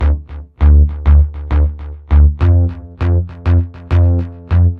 Dance music bass loops 2
Dance music bass loop - 100bpm 51